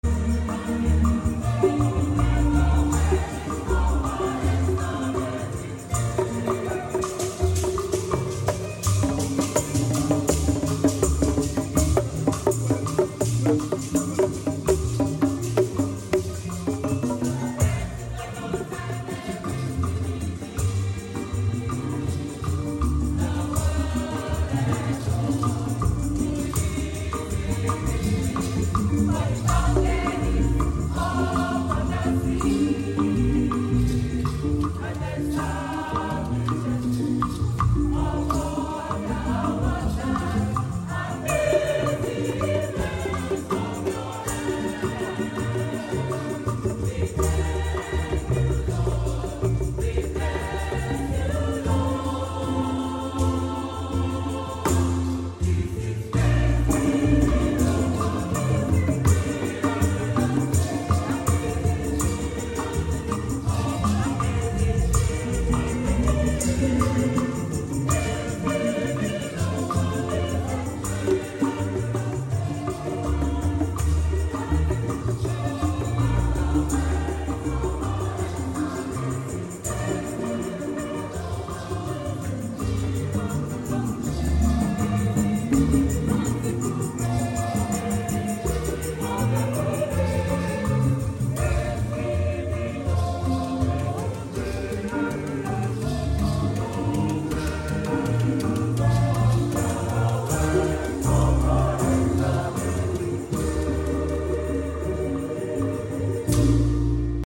Saturdays are for weddings